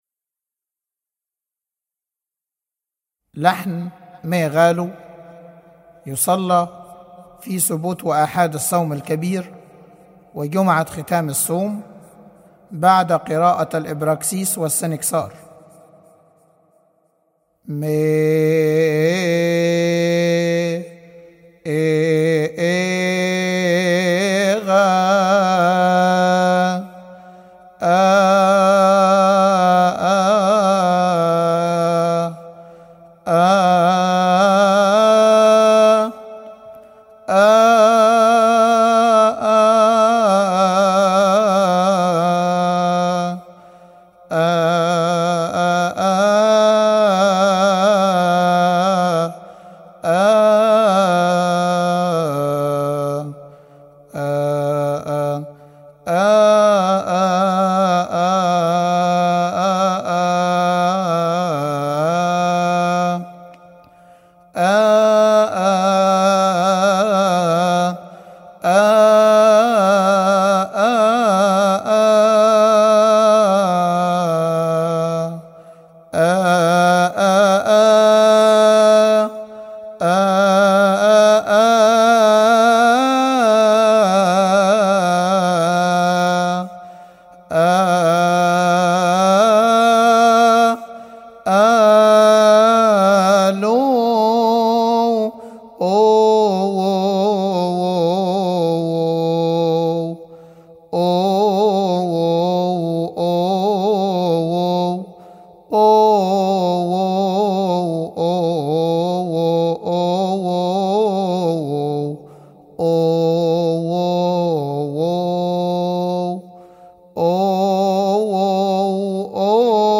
لحن ميغالو
استماع وتحميل لحن لحن ميغالو من مناسبة som-kebir